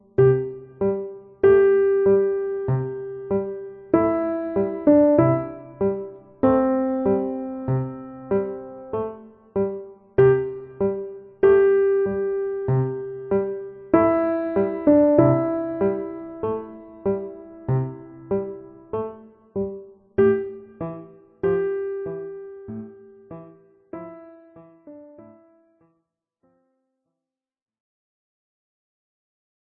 Level 1-Easy Piano Solos
A simple melody over a traditional 4-note ostinato make